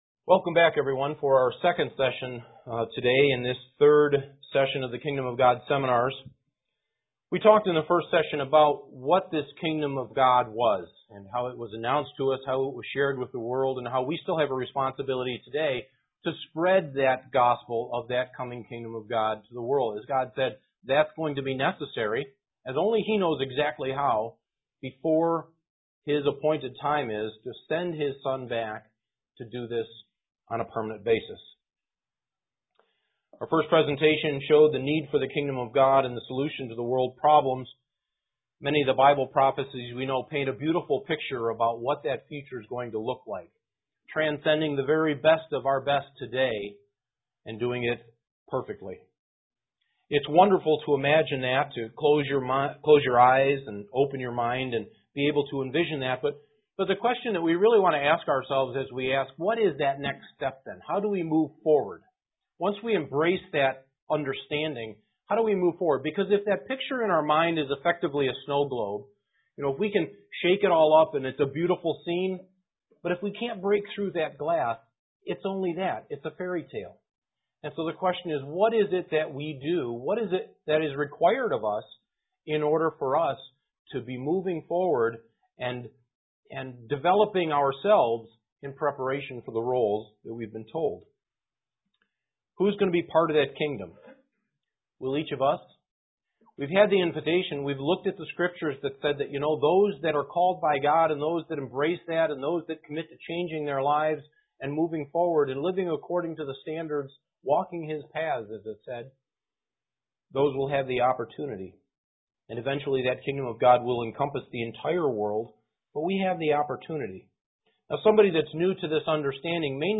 Kingdom of God Seminar 3 Lecture 2
Given in Elmira, NY
Print Kingdom of God Seminar 3 Lecture 2 UCG Sermon Studying the bible?